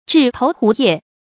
雉頭狐腋 注音： ㄓㄧˋ ㄊㄡˊ ㄏㄨˊ ㄧㄜˋ 讀音讀法： 意思解釋： 以雉頭狐腋做成的裘衣。